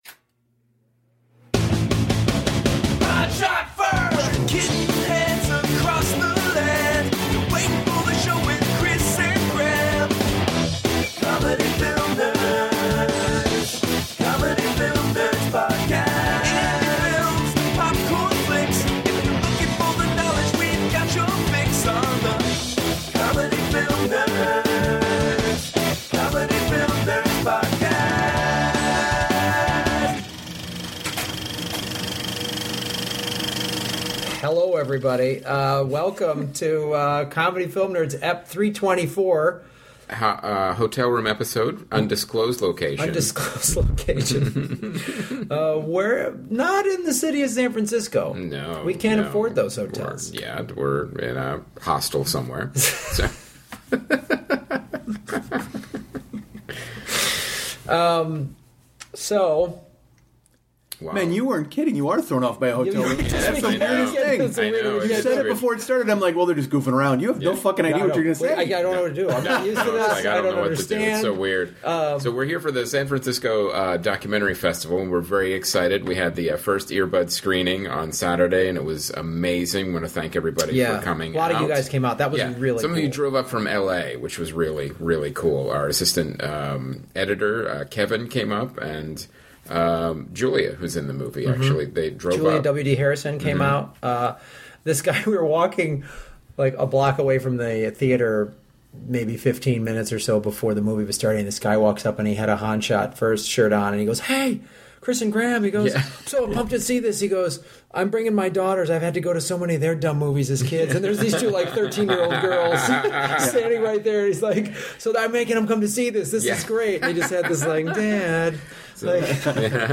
record from a hotel in the Bay Area